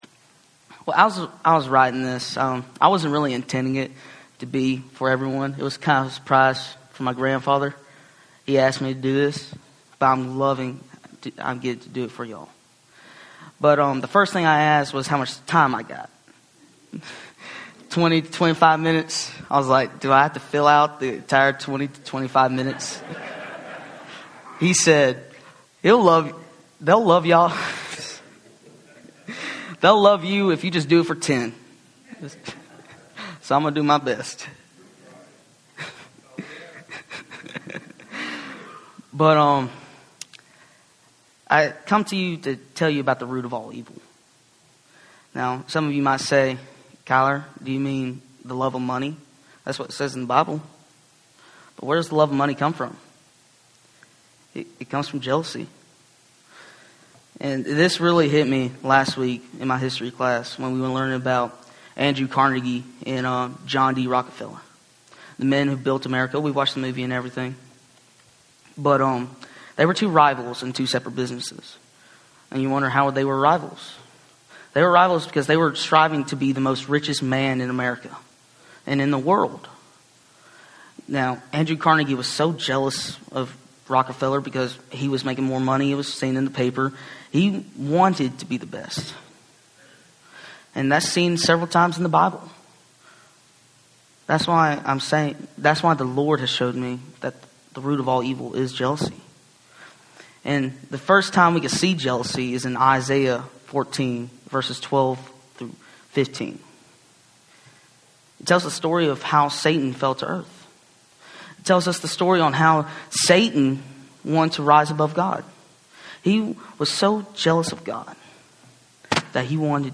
Audio Sermon Video Sermon Save Audio http